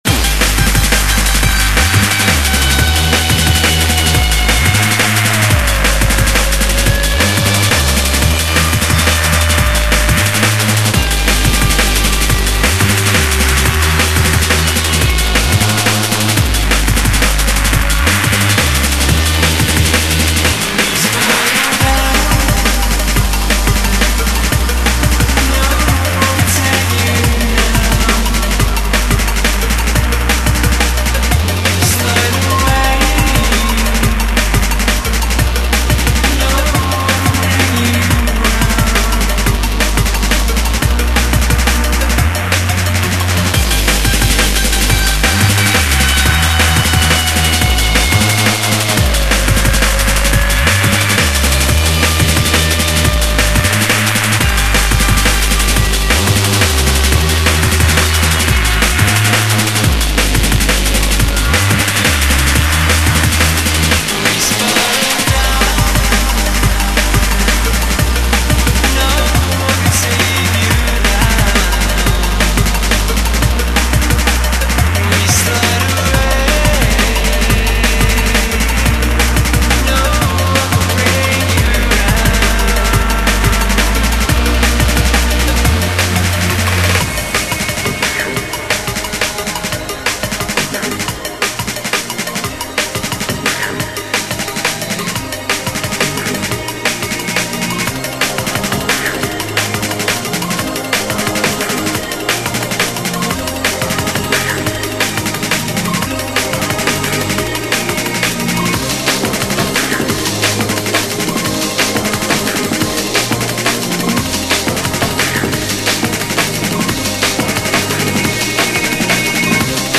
D-n-B